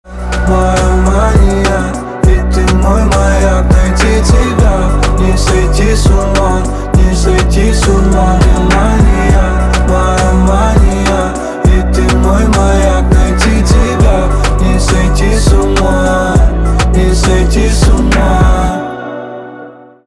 русский рэп
битовые , басы
чувственные